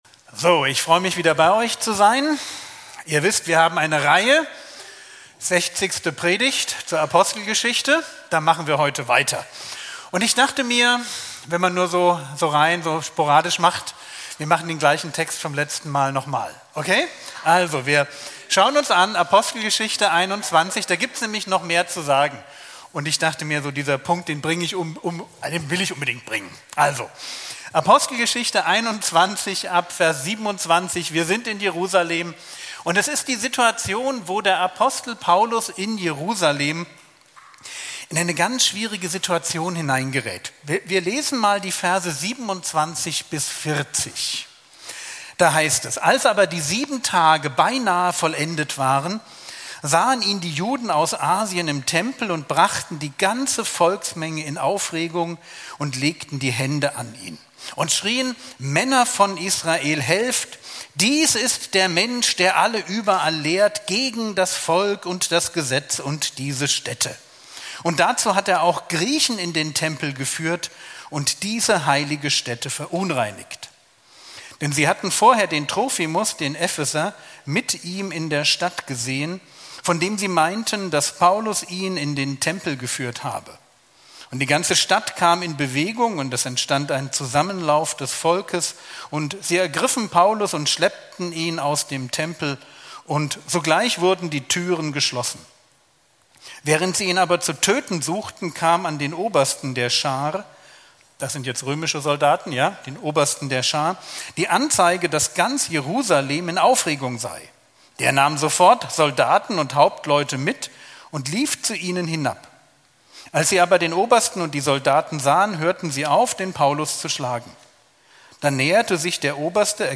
Predigt Apostelgeschichte 21, 27-40